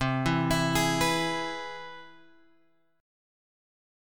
C Augmented Major 7th